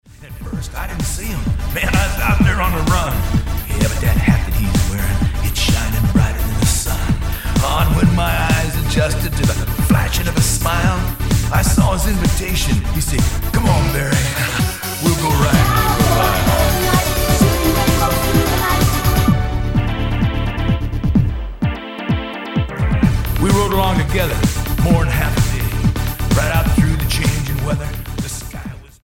Style: Dance/Electronic Approach: Praise & Worship